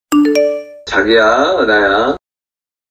Genre: Nada dering Korea
nada notifikasi Korea